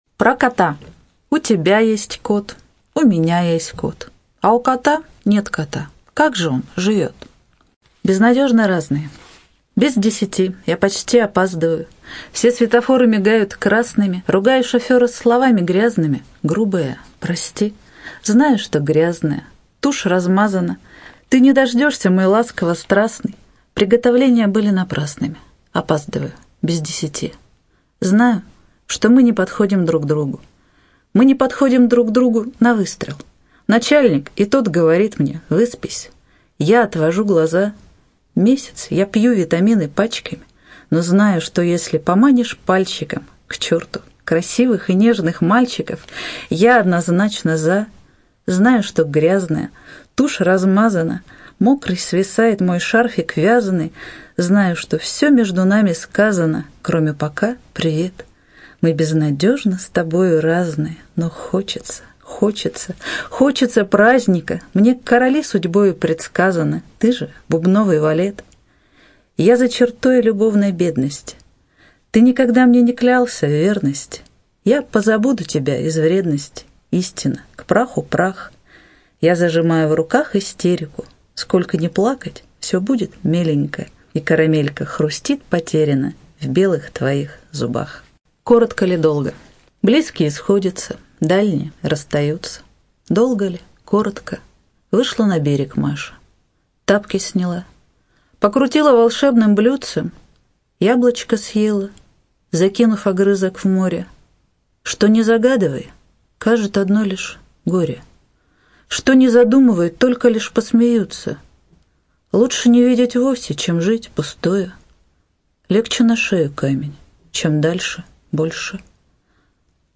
ЧитаетАвтор
Студия звукозаписиНовосибирская областная специальная библиотека для незрячих и слабовидящих